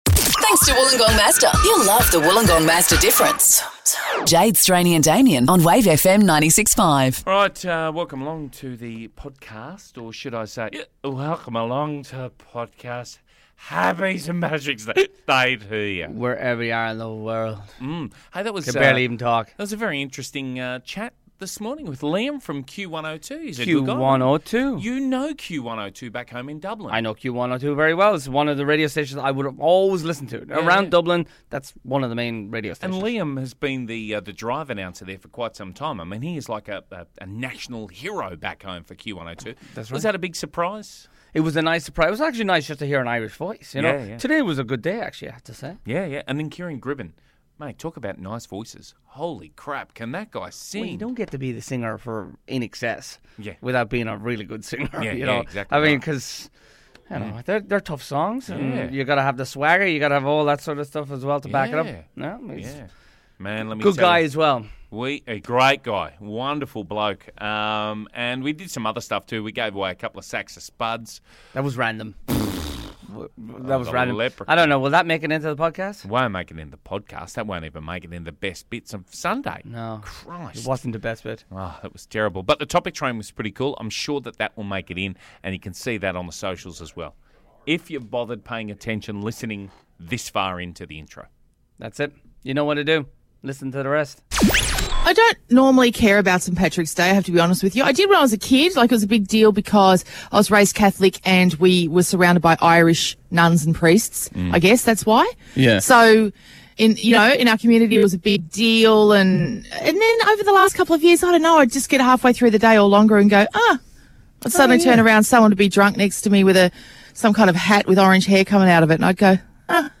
St. Patrick's Day chat
performs an Irish song mash-up on the Rooftop